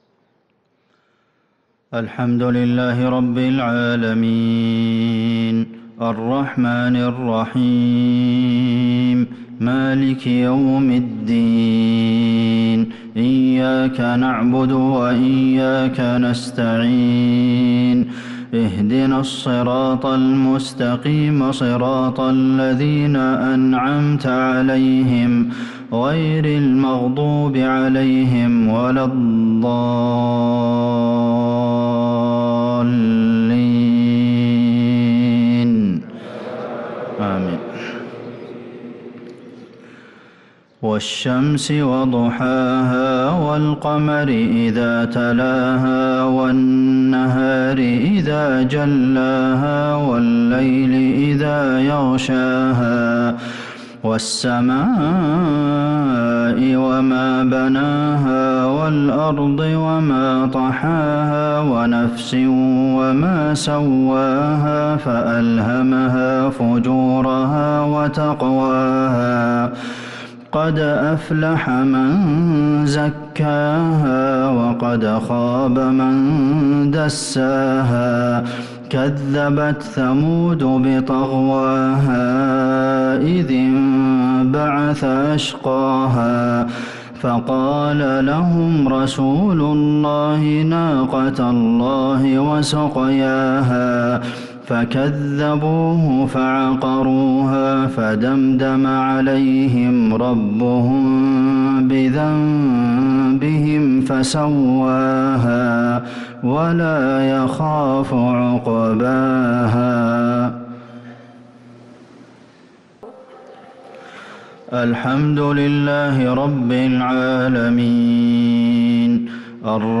صلاة المغرب للقارئ عبدالمحسن القاسم 7 ربيع الأول 1444 هـ
تِلَاوَات الْحَرَمَيْن .